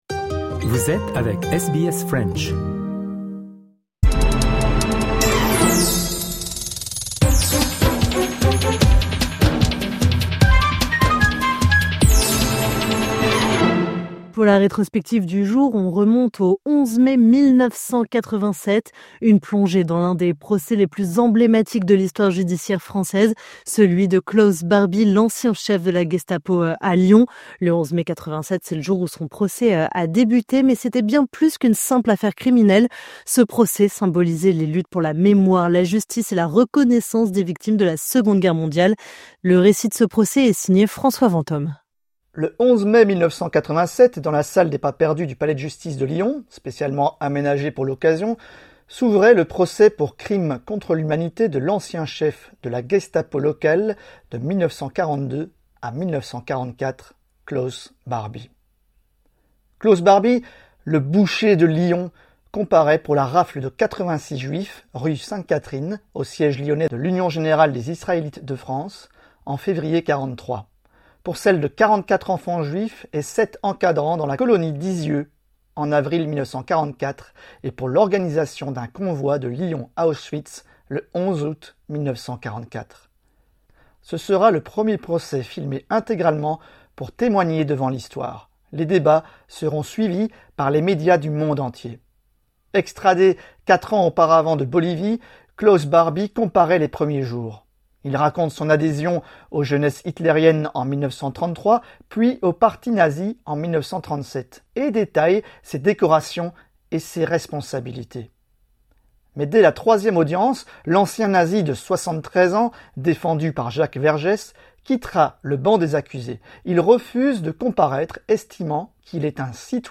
Récit